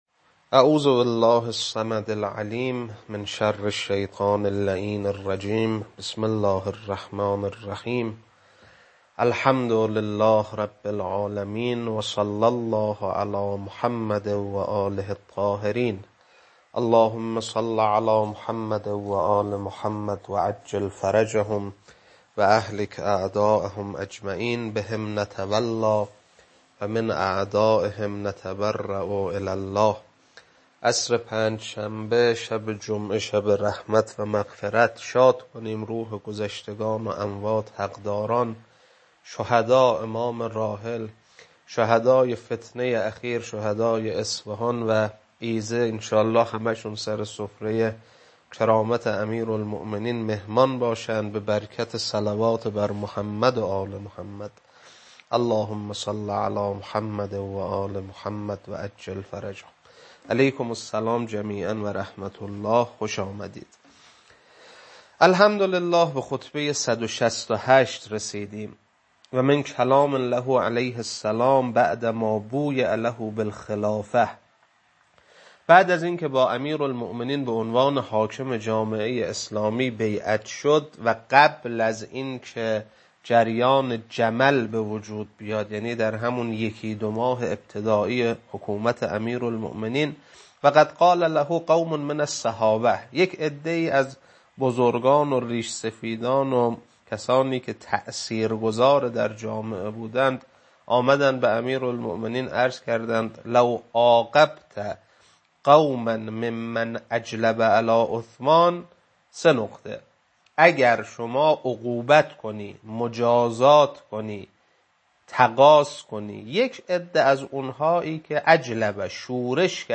خطبه 168.mp3